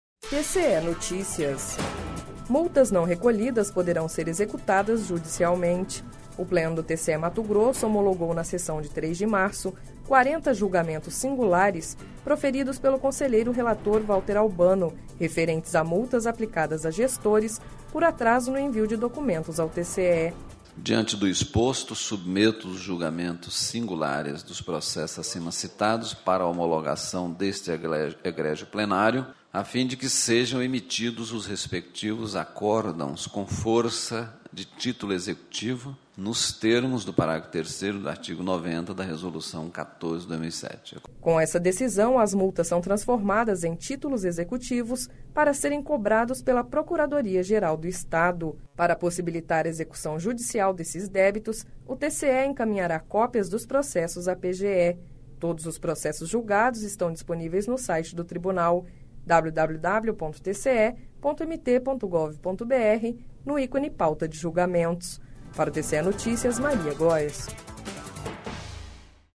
O Pleno do TCE-MT homologou, na sessão de 03 de março, 40 julgamentos singulares proferidos pelo conselheiro relator Valter Albano, referentes a multas aplicadas a gestores por atraso no envio de documentos ao TCE.// Sonora: Valter Albano – conselheiro do TCE-MT